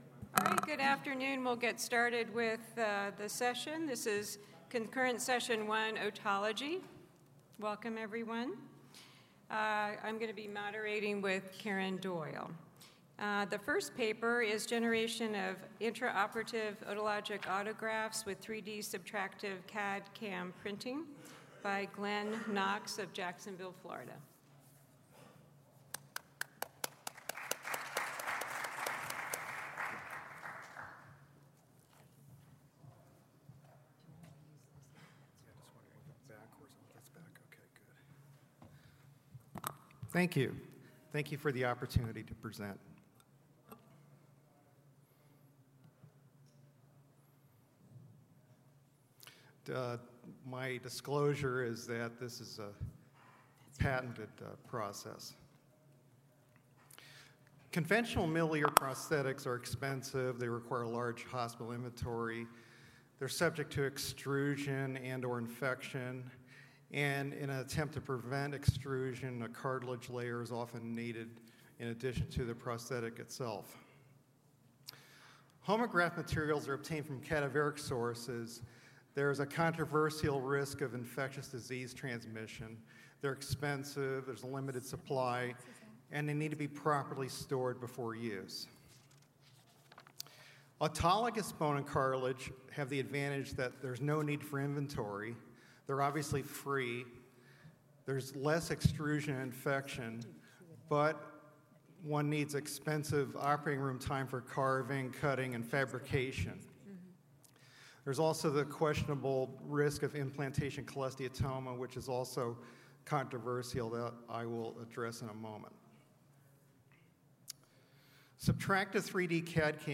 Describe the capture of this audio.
Speakers at the Triological Society’s 2014 Combined Sections Meeting present their work in otology.